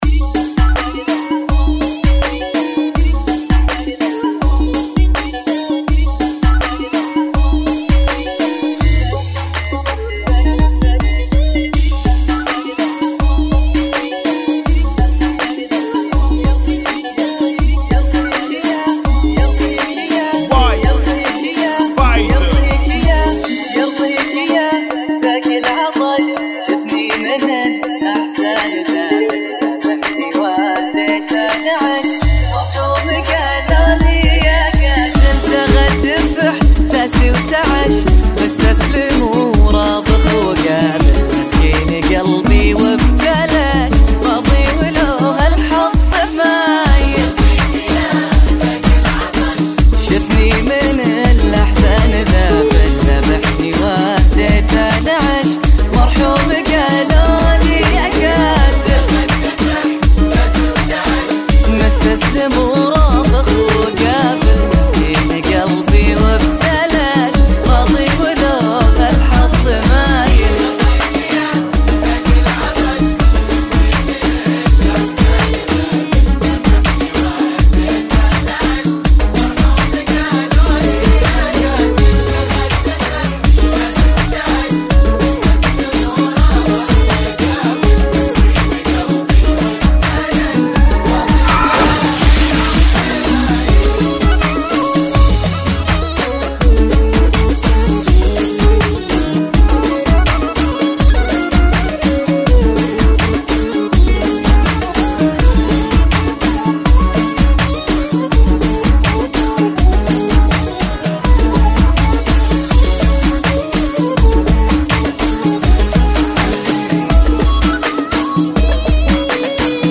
Funky [ 82 Bpm ]